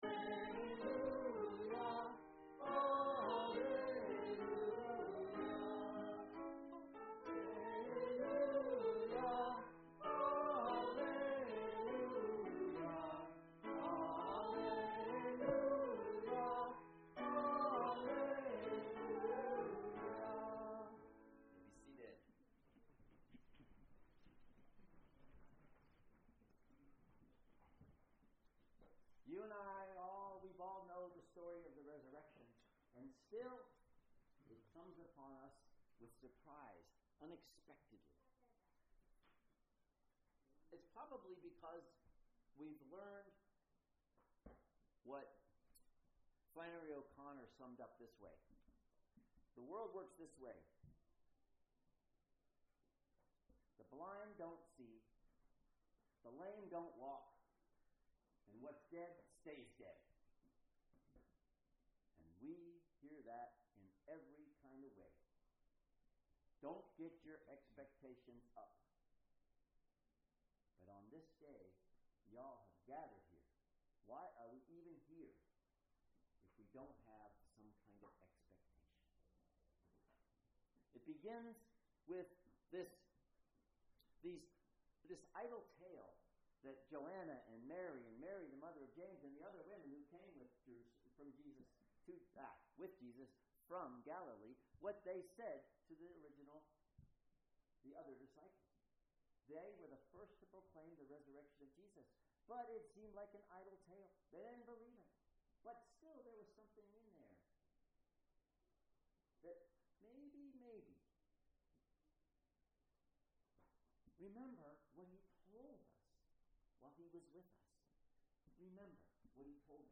Sermons | Lake Chelan Lutheran Church
Easter Day Service